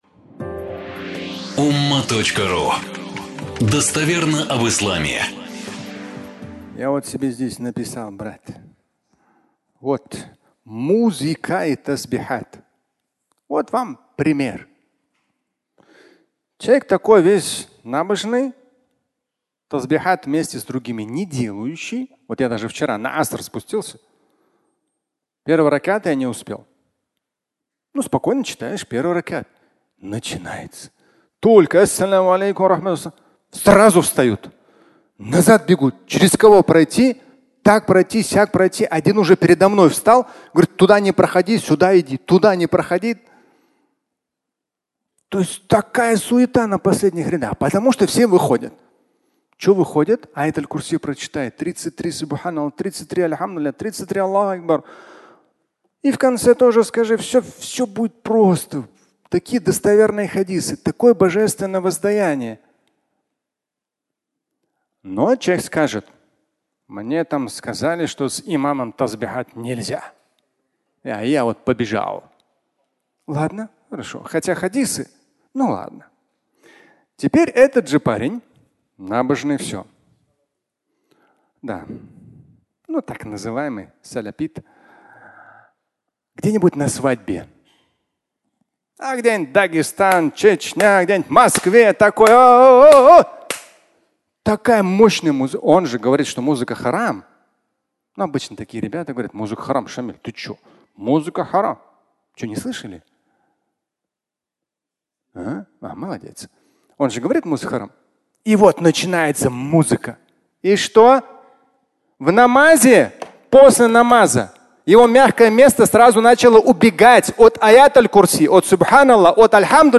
Музыка и тасбихат (аудиолекция)